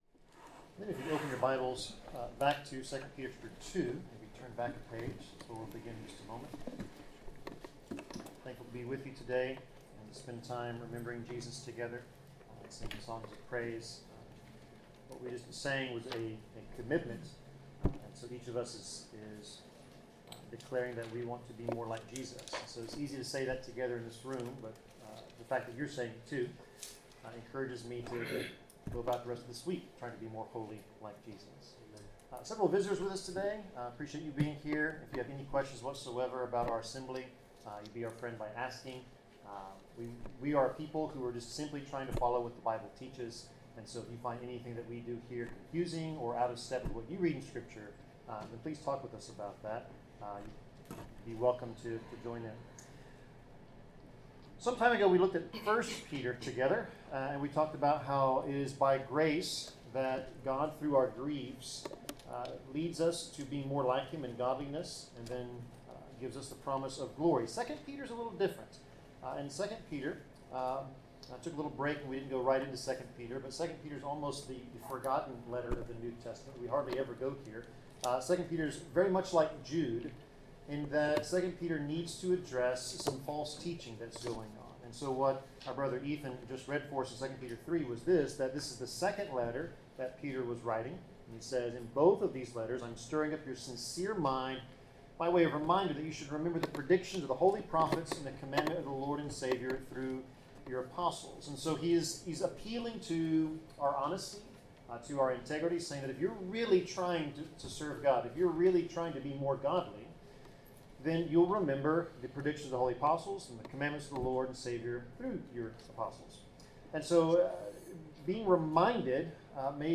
Passage: 2 Peter 2 Service Type: Sermon